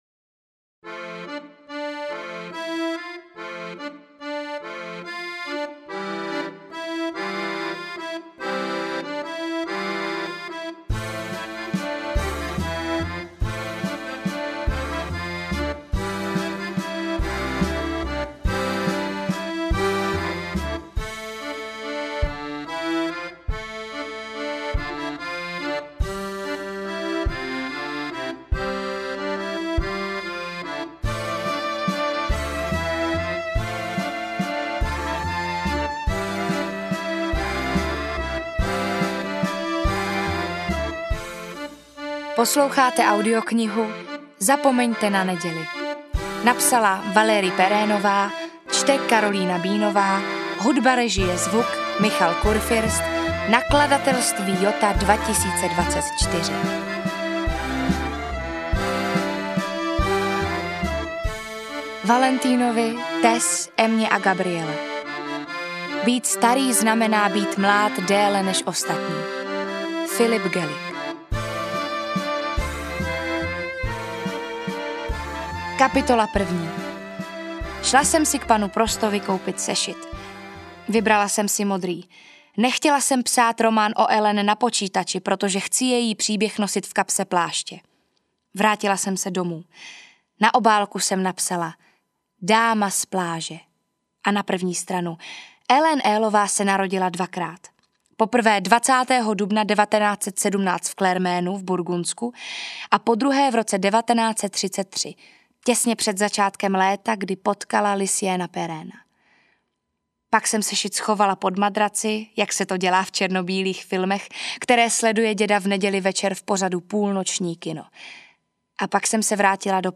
AudioKniha ke stažení, 12 x mp3, délka 9 hod. 14 min., velikost 506,5 MB, česky